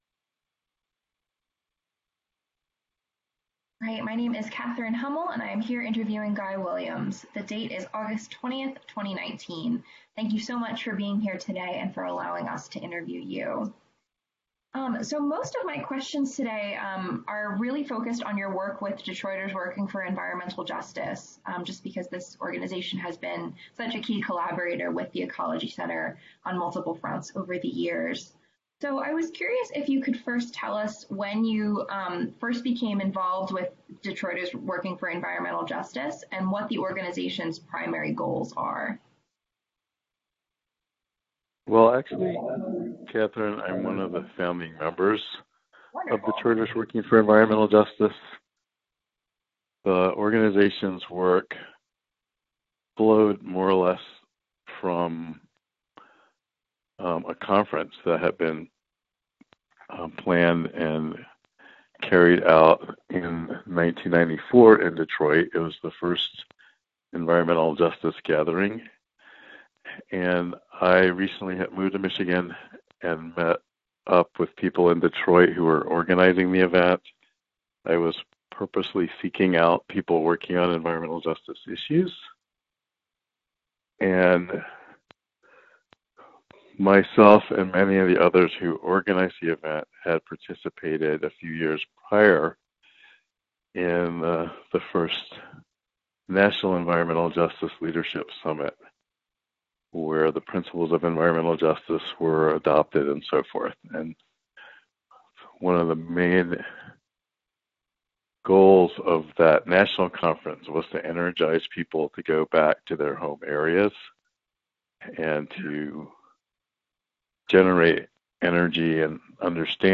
Ecology Center Interviews